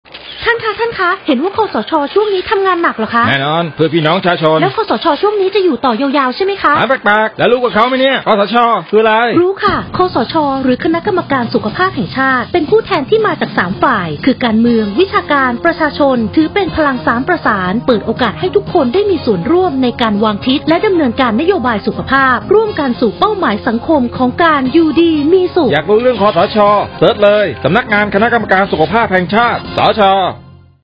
สปอตวิทยุ คสช. แปลว่า ?